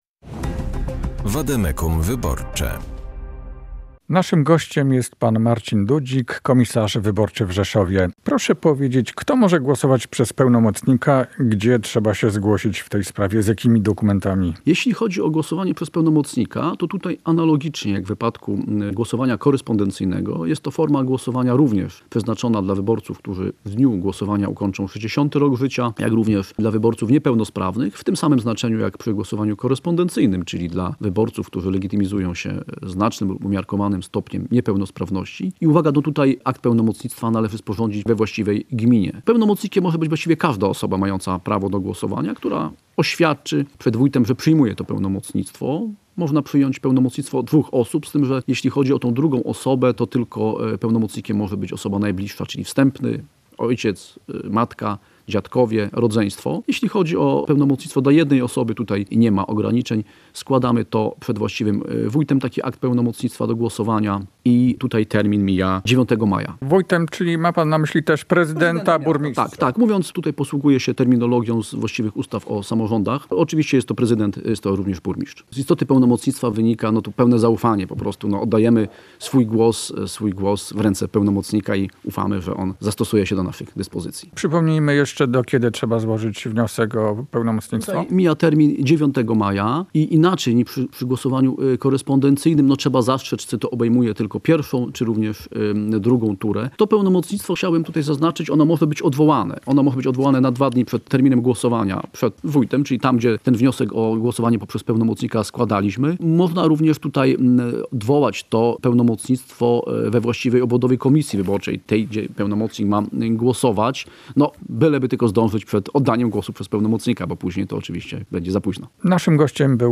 – wyjaśnia komisarz wyborczy w Rzeszowie Marcin Dudzik.
Tekst i rozmowa